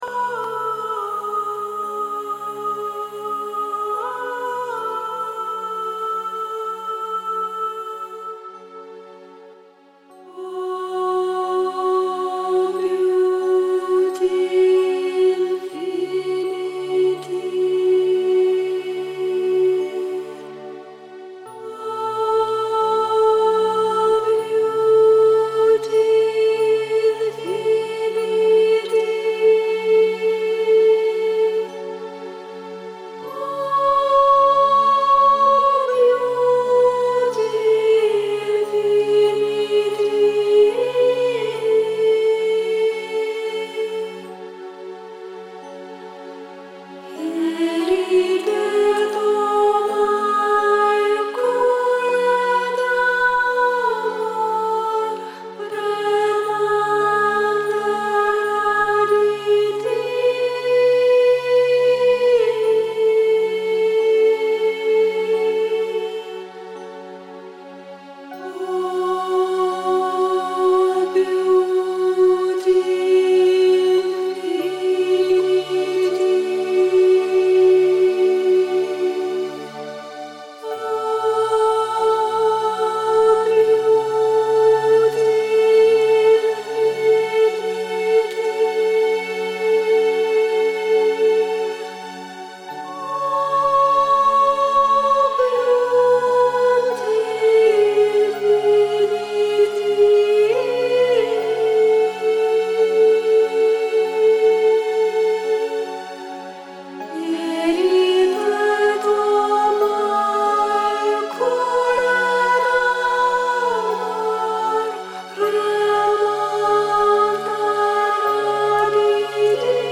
Запись женского вокала (7 девушек).
У нас музыкальная любительская группа. 7 женских голосов, синтезатор, гитара.
Я типа тех.обеспечение))) Уже записывались сами раньше в домашней студии.
Записывали через Беринджер. Выбирали наиболее подходящий теплый тембр.